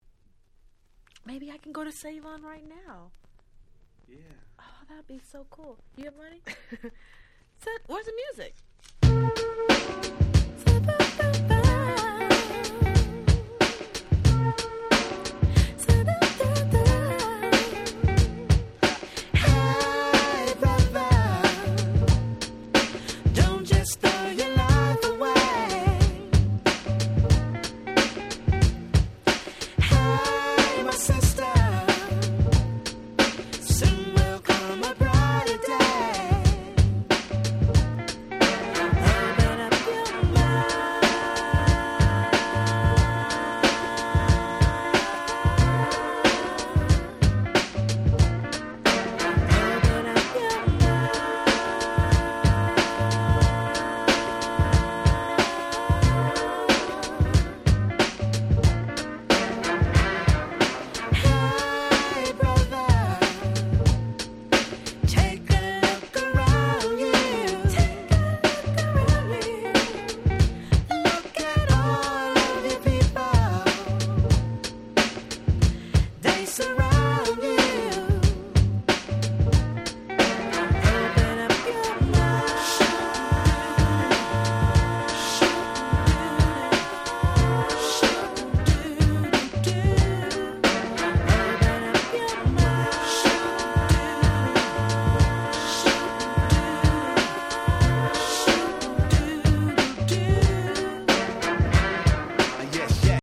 97' Nice R&B/Neo Soul !!